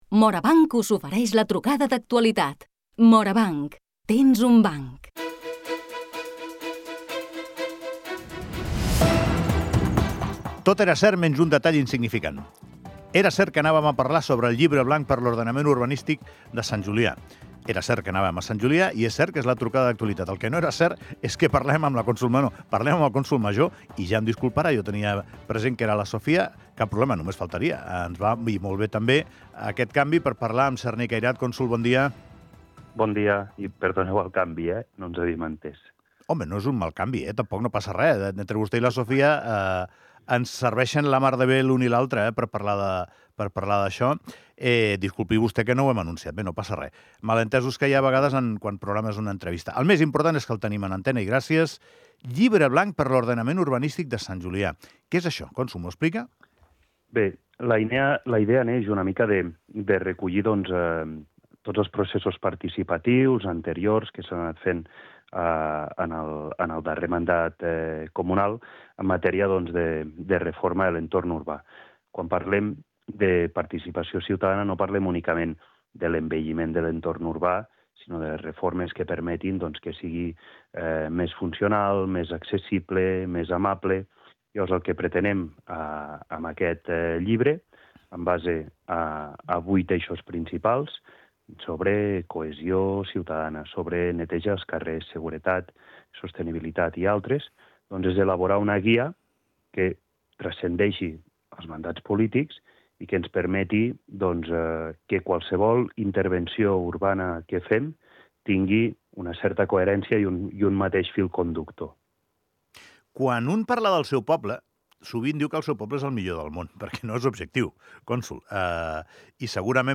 De moment, el comú començarà per la plaça Calonge i seguirà pel centre. En parlem amb el Cònsol Major Cerni Cairat.
Trucada actualitat sobre l'embelliment urbà de Sant Julià amb Cerni Cairat